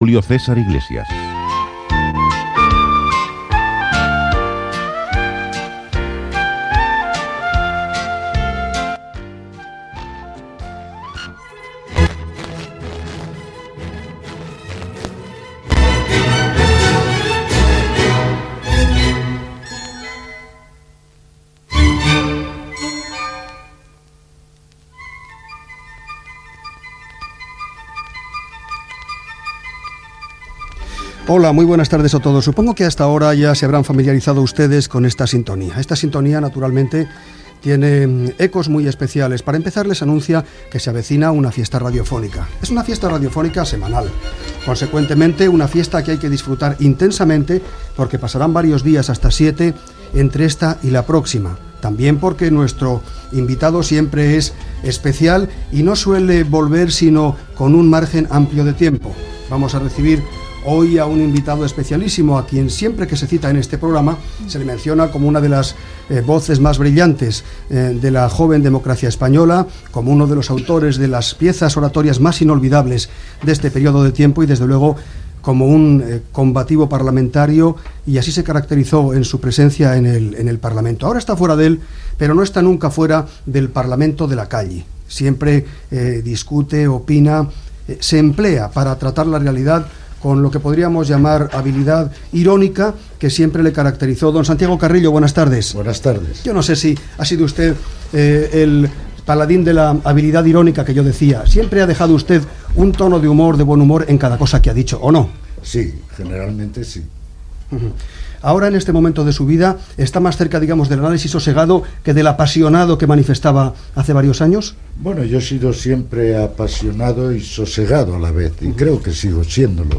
Indicatiu del programa, promoció de "Carrusel deportivo", publicitat. El president del govern espanyol Felipe González (imitació) i el vicepresident Alfonso Guerra (imitació) parlen de Juan Guerra i altres temes amb Santiago Carrillo Gènere radiofònic Entreteniment